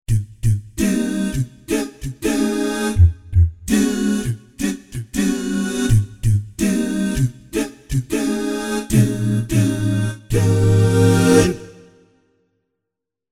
Doo Sfz demo =2-A02.mp3